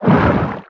Sfx_creature_pinnacarid_push_06.ogg